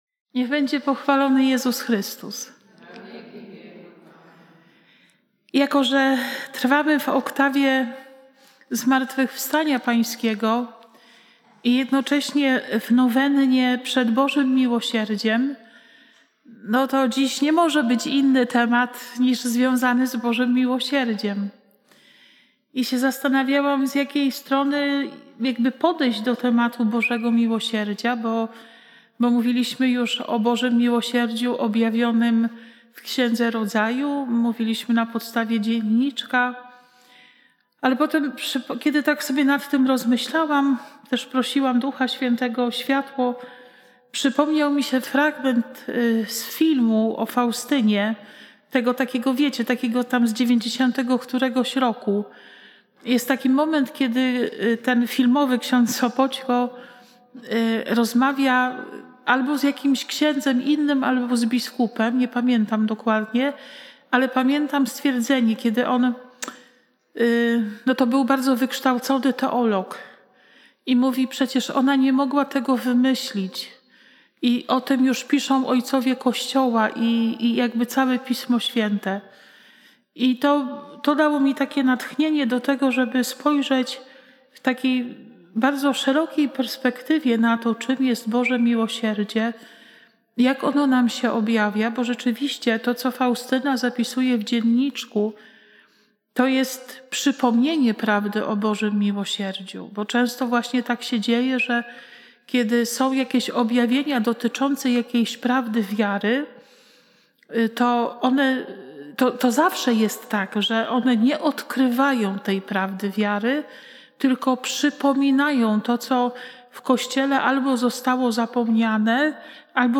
konferencja